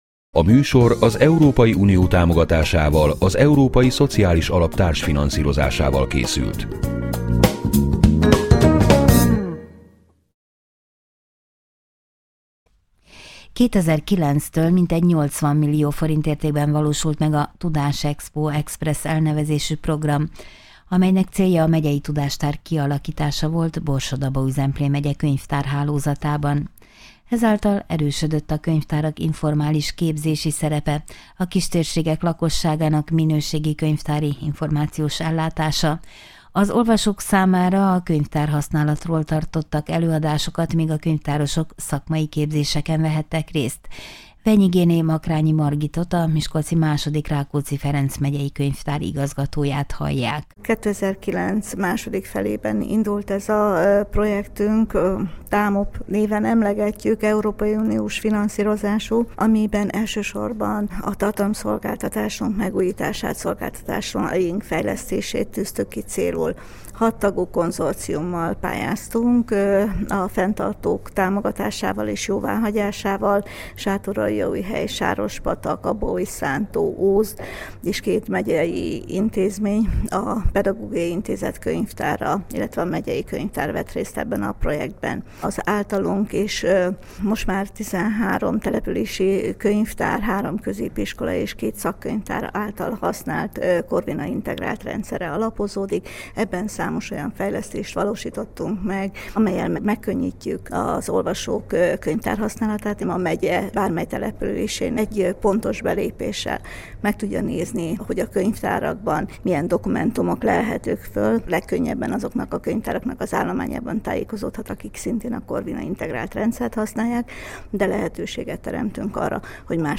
europa-radio_riport.mp3